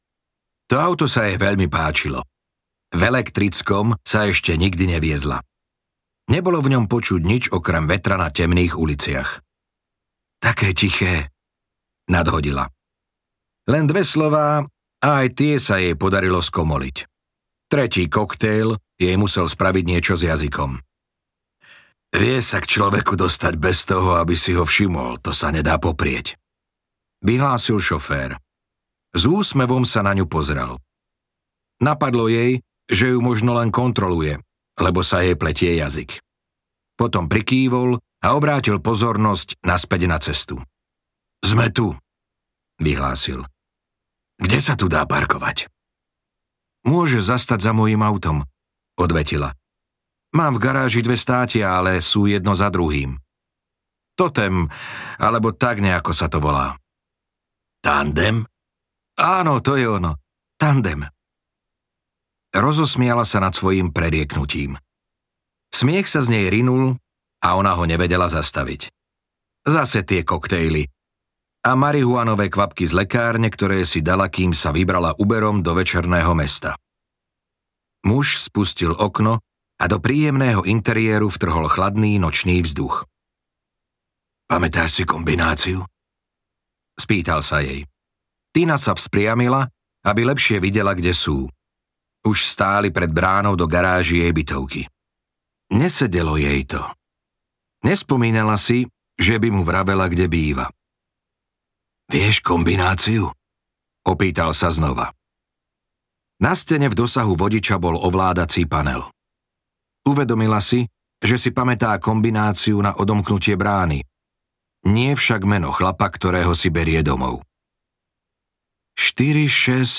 Výstraha audiokniha
Ukázka z knihy